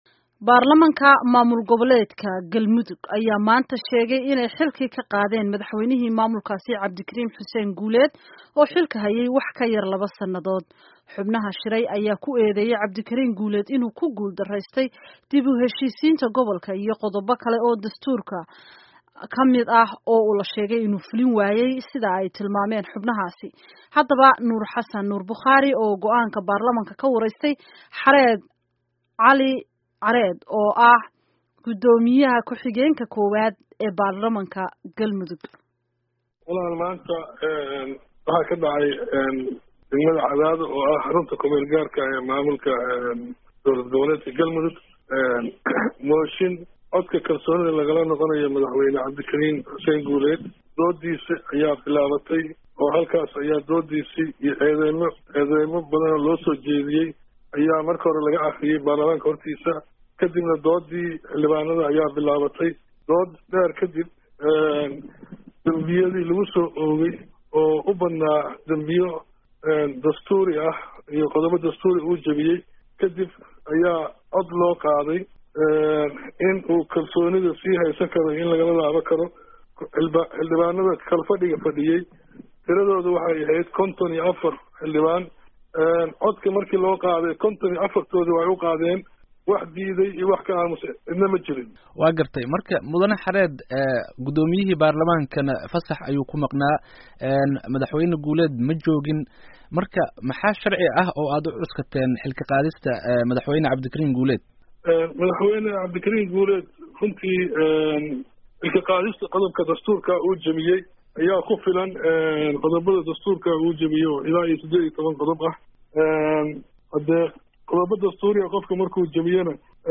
Dhagayso Xareed Cali Xareed Gudoomiye kuxigeenka Baarlamaankaas